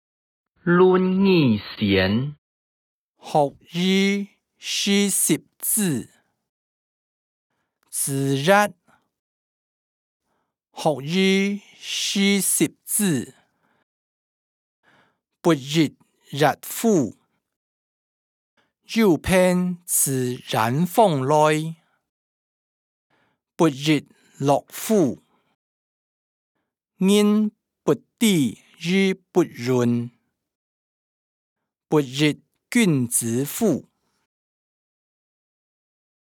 經學、論孟-論語選．學而時習之音檔(海陸腔)